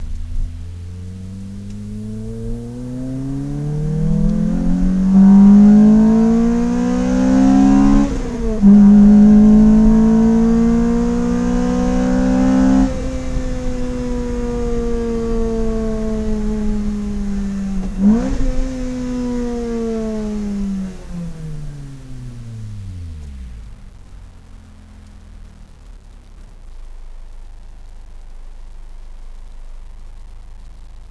チタンマフラー
・純正レイアウトにより横出しマフラーよりも長くチタンパイプを通過する為、音は正に「チタンの音」。
（ノートPC録音の為、音質が悪いですが参考までに）
走行（室内）
soukou.wav